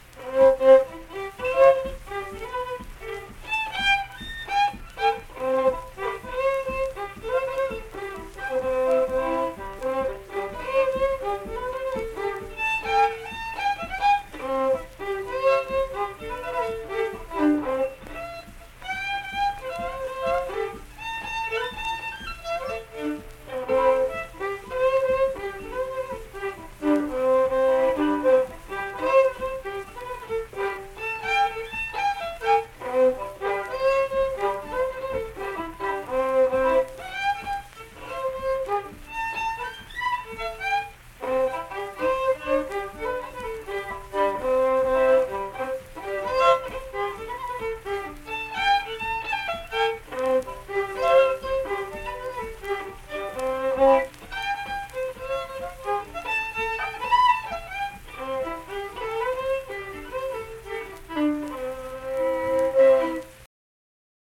(Fiddle Tune)
Unaccompanied fiddle performance
Verse-refrain 2(2).
Instrumental Music
Fiddle